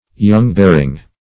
young-bearing \young"-bear*ing\, adj.